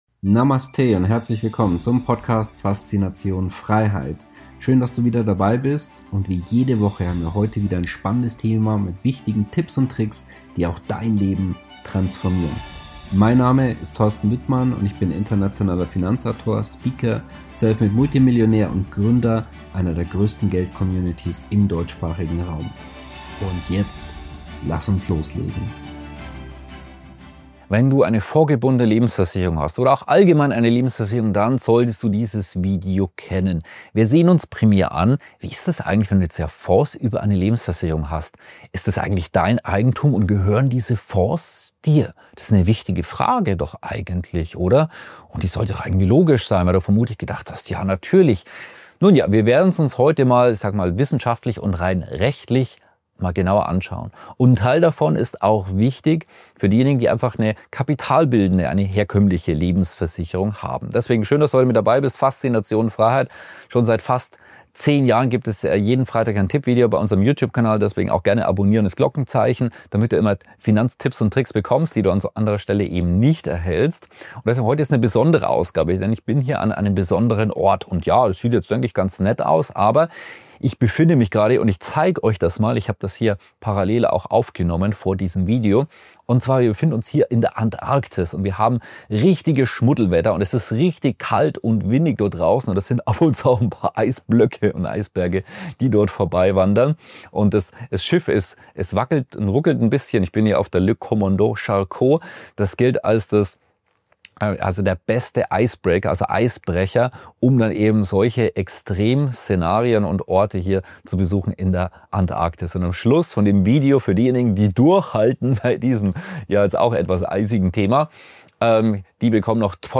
- Ernüchterndes Gesamtfazit: Kosten, Transparenz und Risiko In der heutigen Ausgabe zeige ich dir die rechtliche Lage – wissenschaftlich fundiert, verständlich erklärt. Ich bin gerade in der Antarktis unterwegs und nutze die eisige Kulisse als Metapher: Wer nicht vorsorgt, kann schnell im Kalten stehen.